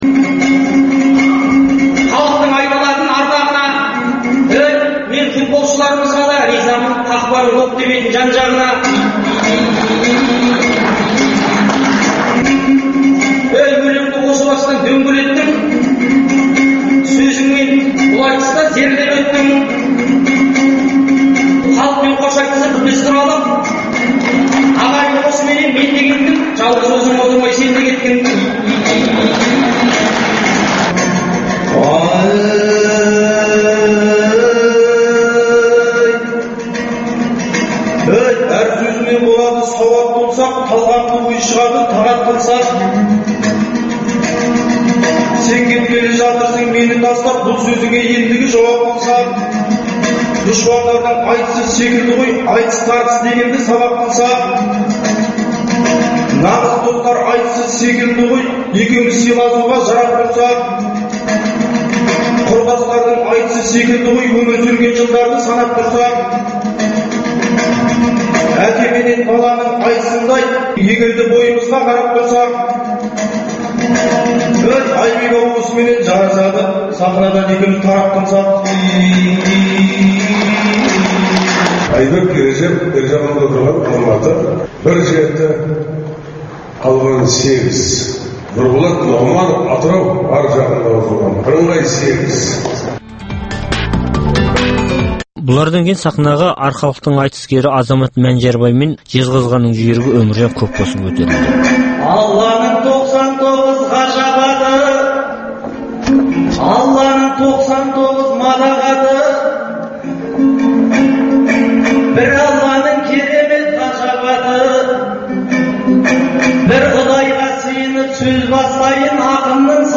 Жақында Астана қаласында өткен жастар айтысынан келесі жұптың сөз сайысын тыңдаңыздар.